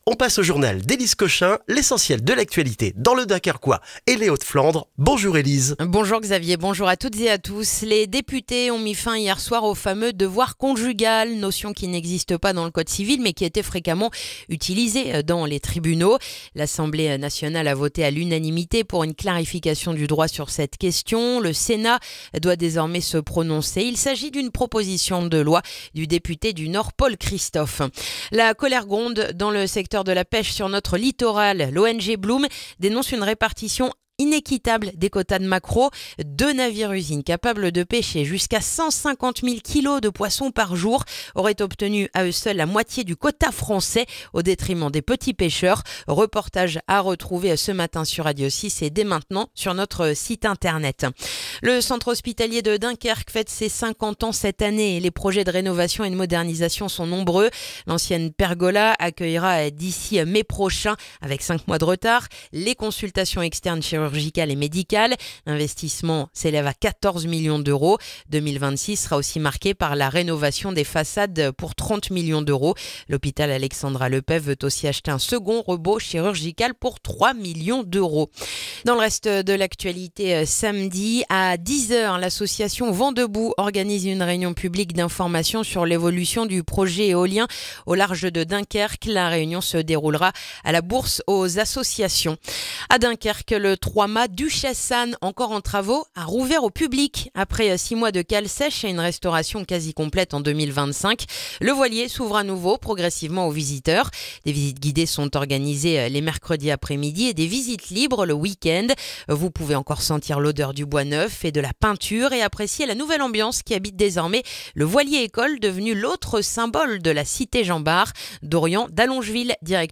Le journal du jeudi 29 janvier dans le dunkerquois